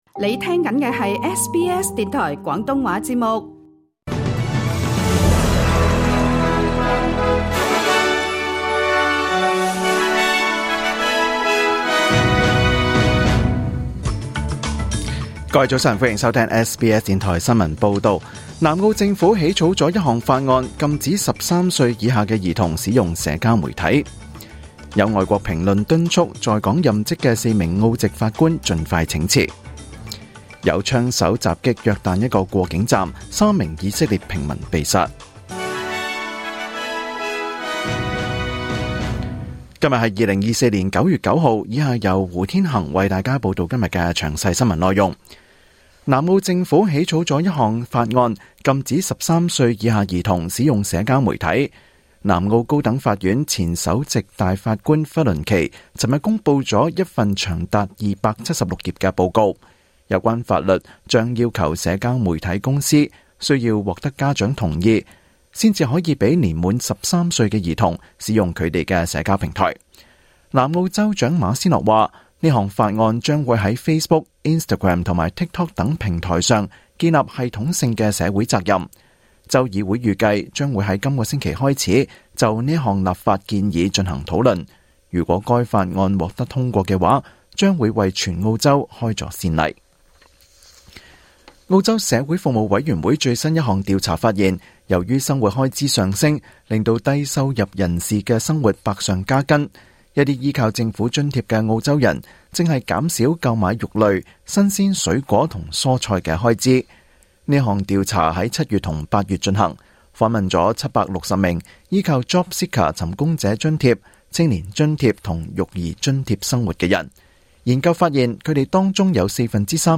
2024年9月9日SBS廣東話節目詳盡早晨新聞報道。